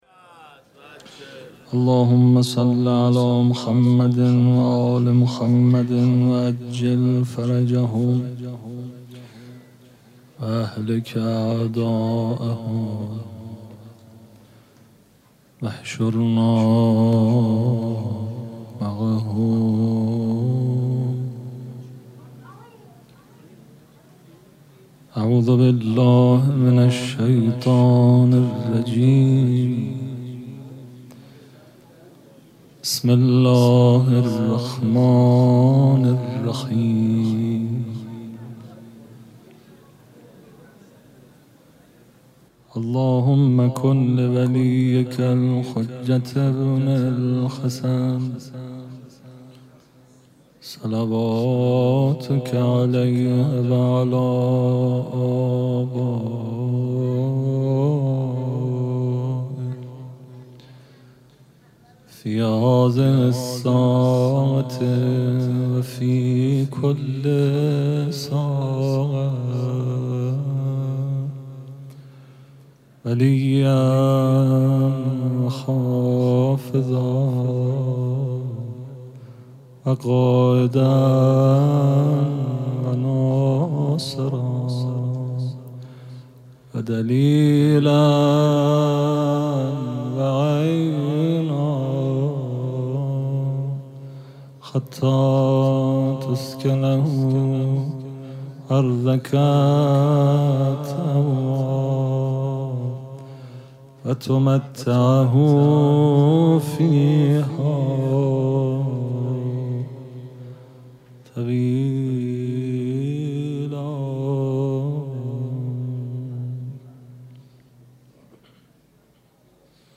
پیش منبر